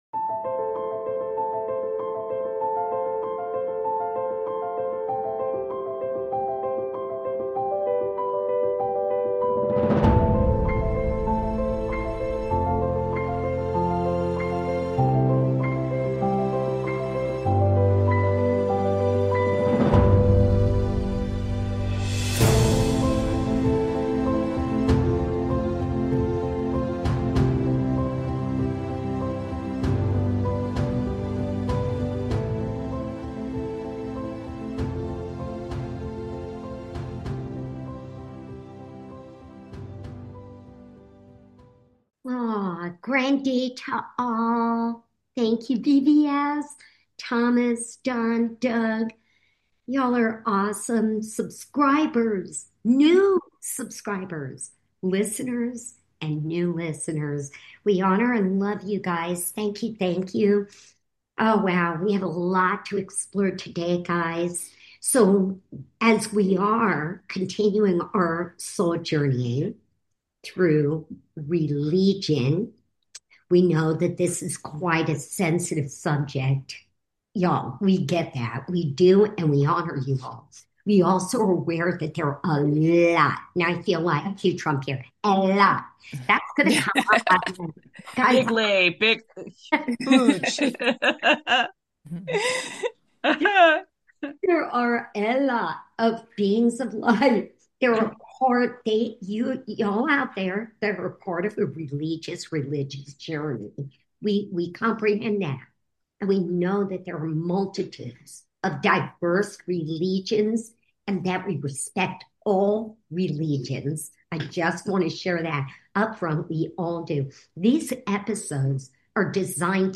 Occasionally, we'll also take "call-ins" and conduct "one-on-one" interviews.